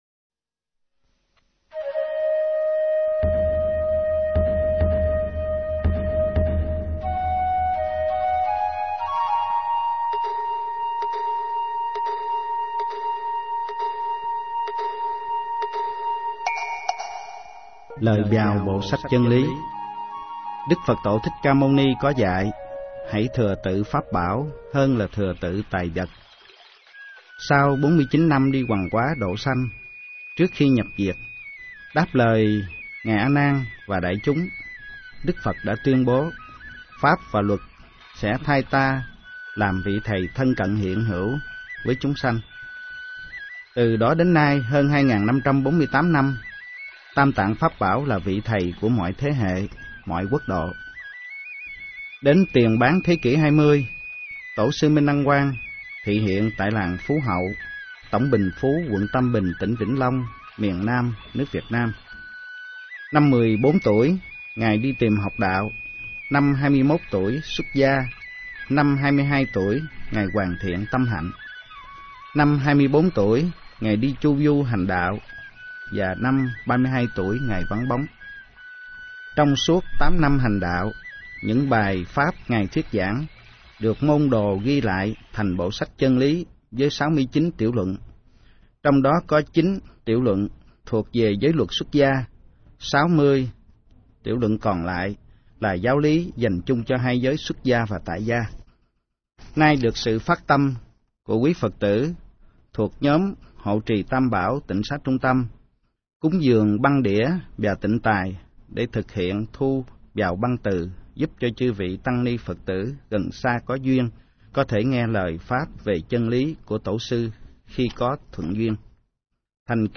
Nghe sách nói chương 01. Võ Trụ Quan